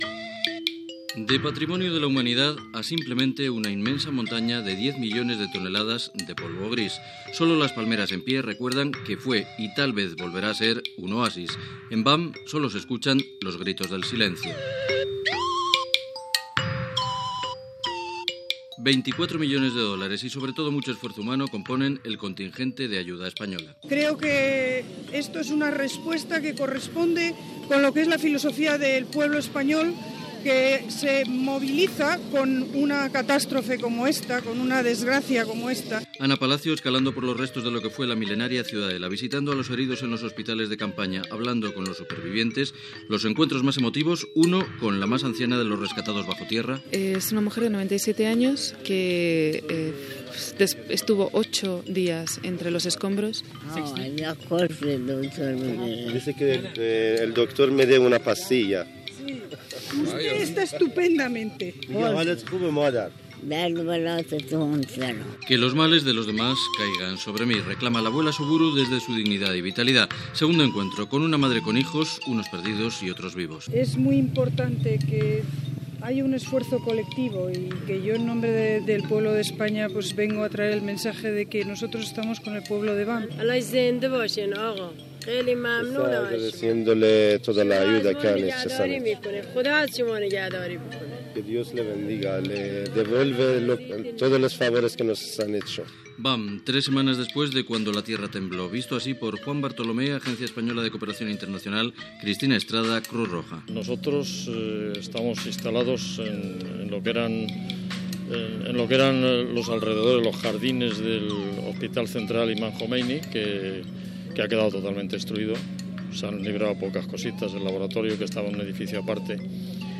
Informació sobre la situació d'emergència a a Bam, Iran, tres setmanes després que patís un fort terratrèmol el 26 de desembre de 2003, Entrevista a diferents voluntaris espanyols i a la ministra espanyola Ana Palacios
Informatiu